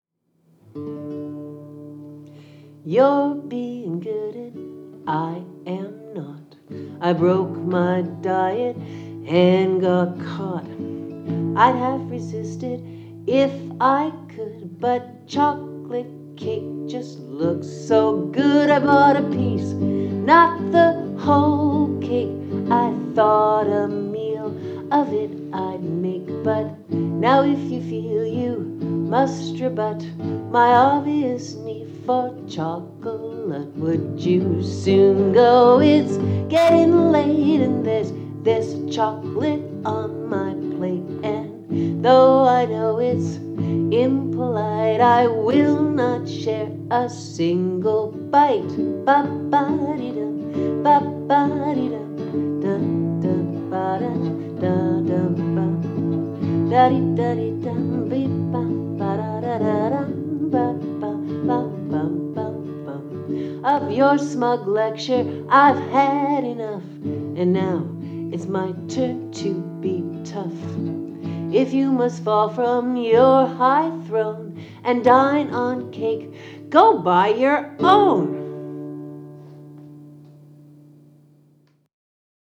Strangely enough, when I copied the above photos onto the media file, this song just added itself.